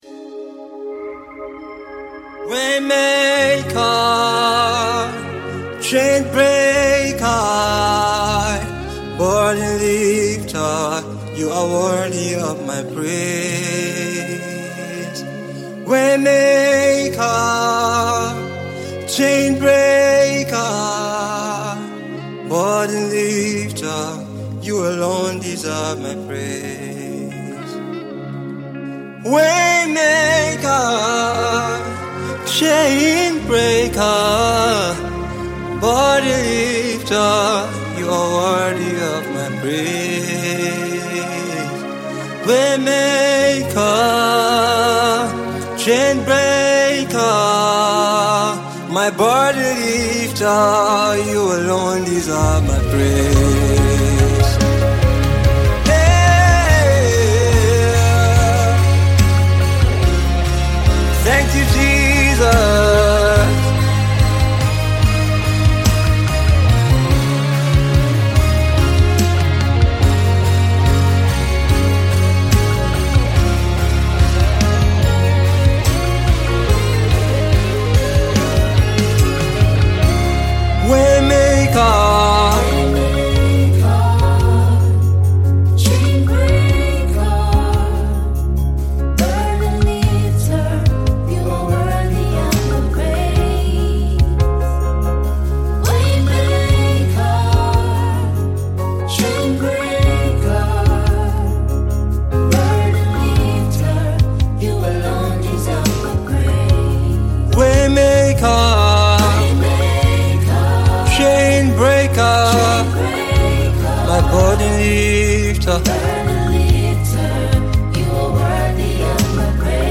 Gospel singer
powerful worship song